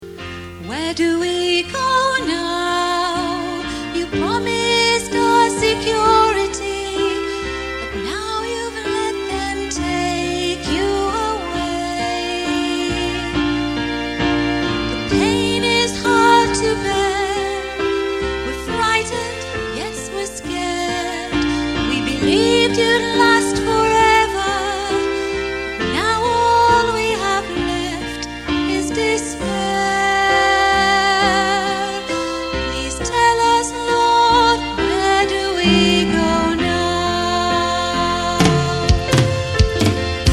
MPEG file of the first verse (631kb)